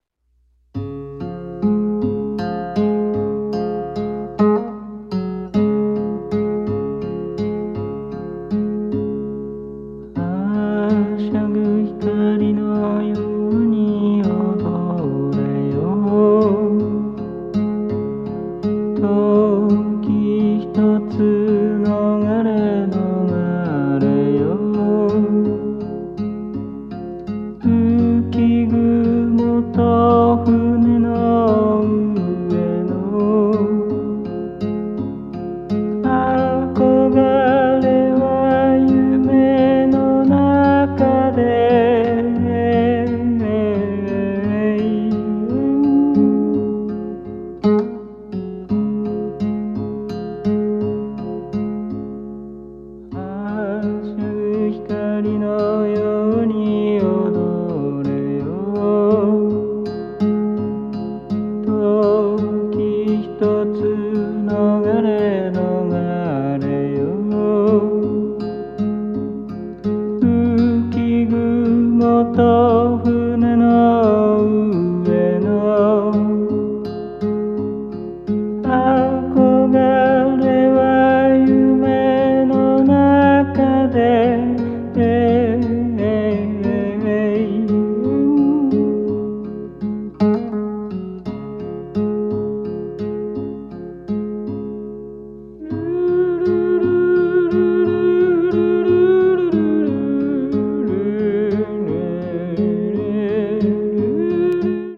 仄暗くもロマンチックな風情をまとったフォーキーな歌が、詩的な哀愁を帯びて響いてくる好内容！